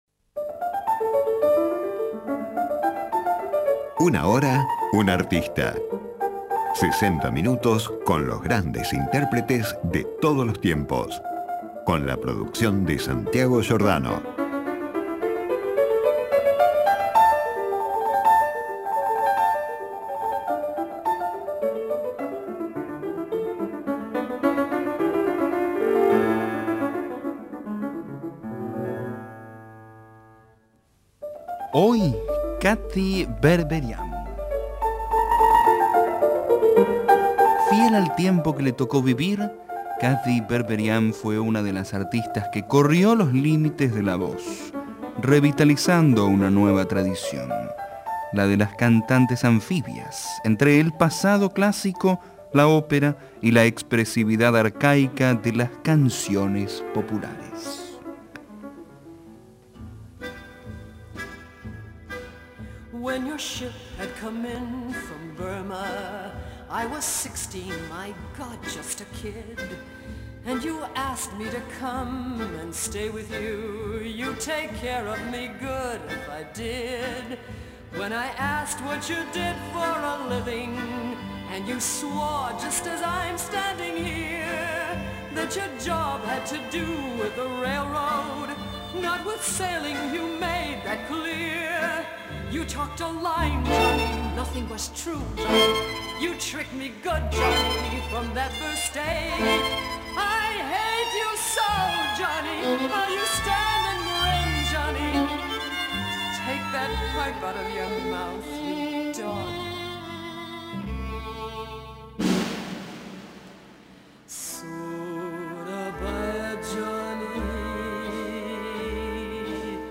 En esta oportunidad, repasamos la carrera de esta peculiar mezzosoprano que corrió los límites de la voz, aportando al canto operístico un estilo único. A la par, Berberian incursionó en el folclore tradicional y la música de su tiempo, rompiendo los moldes de lo que se podía esperar en su época de una cantante de ópera.